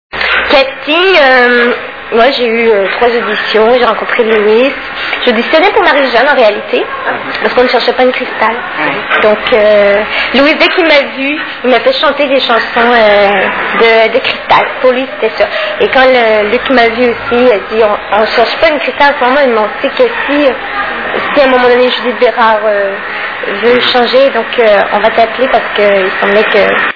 STARMANIA...Interview
( Casino de Paris, Hall d'entrée, 06/02/2000 )